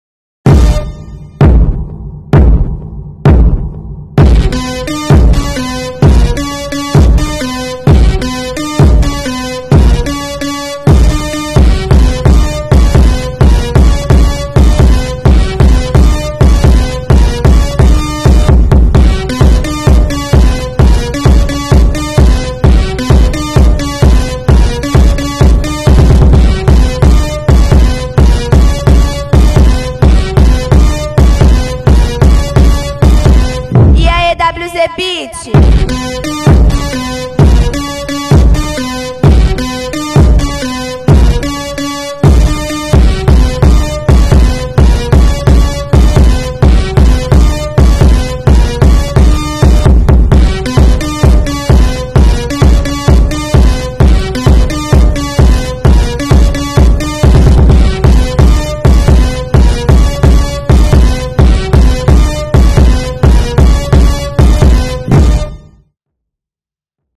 Chicken, Cat Cute Vs Dog Sound Effects Free Download